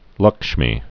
(lŭkshmē)